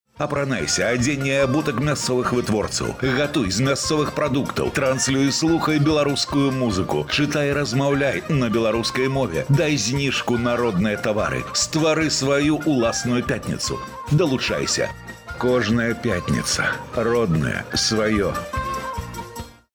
8_МАРТ Роднае Свае Радиорекклама-5